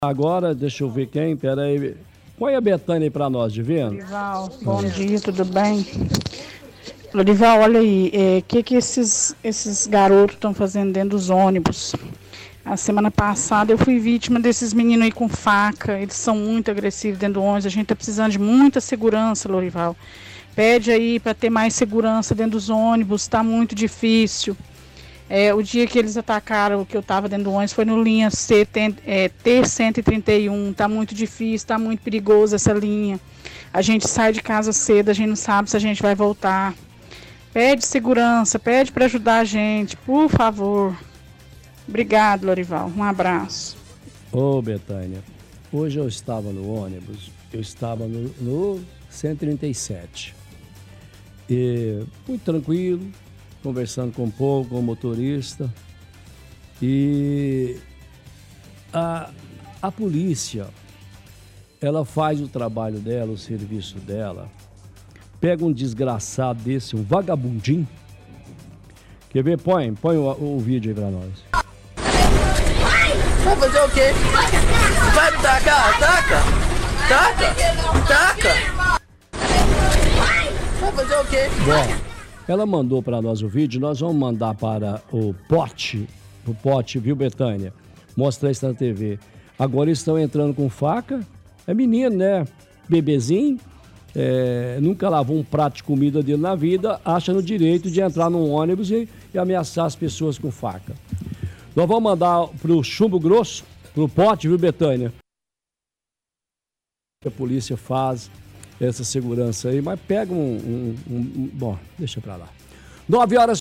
– Ouvinte reclama de turma de adolescentes que estão entrando dentro de ônibus e ameaçando os usuários do transporte público com faca. Pede por mais segurança dentro dos transportes públicos da cidade.
– Ouvinte questiona segurança de transporte públicos da cidade.